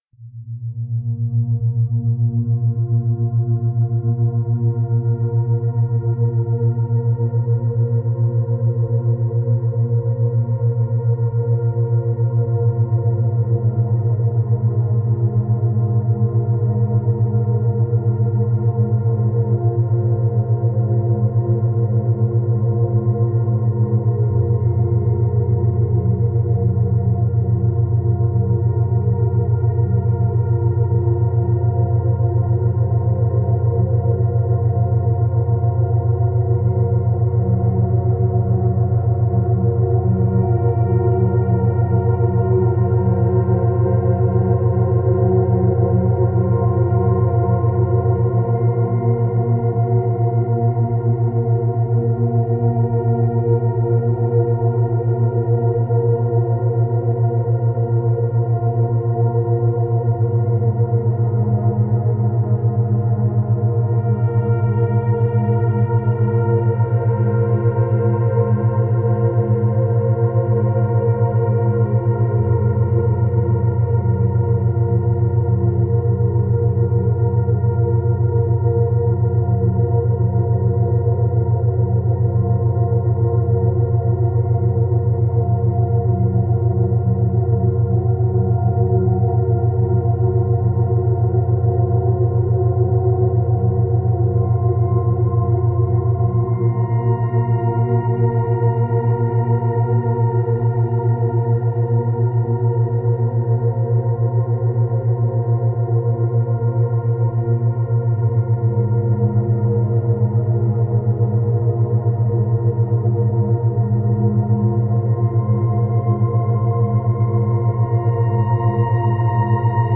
112Hz – 119Hz
در این فصل قرار هست با ضرباهنگ‌های دوگانه آشنا بشیم. گروهی از این فرکانس‌ها به خواب و آرامش شما کمک می‌کنن و گروه دیگشون برای افزایش تمرکز هستن، این امواج میتونن در حین مدیتیشن، حین انجام کار و یا قبل خواب گوش داده بشن.